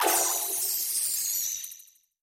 Звуки магии
Звук магического всплеска